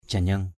/ʥa-ɲʌŋ/ (d.) vũ nữ lễ Rija = danseuse de la fête de rija. dancer of the Rija festival.